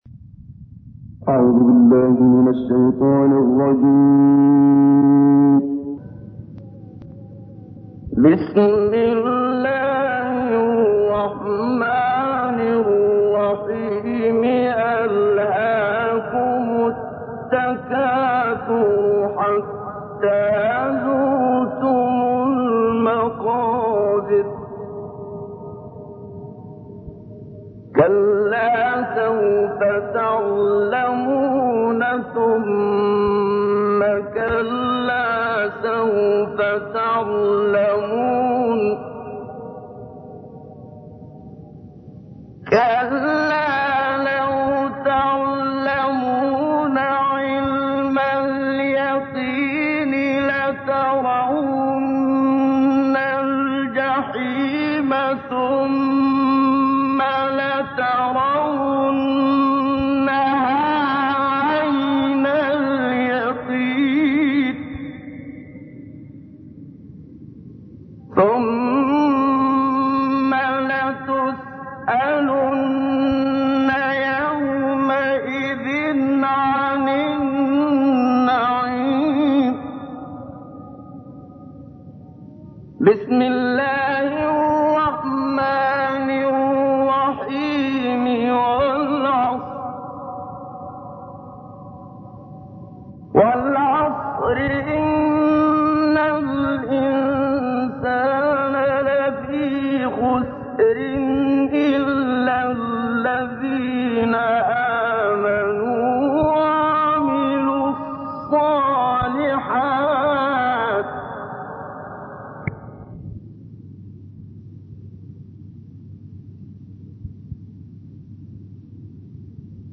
تلاوتی زیبا و شنیدنی از منشاوی/ آیاتی از قصار سور، حمد و بقره+صوت
به گزارش خبرنگار قرآن و فعالیت‌های دینی خبرگزاری فارس محمد صدیق منشاوی قاری نامدار جهان اسلام متولد ۱۹۲۰ و درگذشته ۱۹۶۶ کشور مصر است.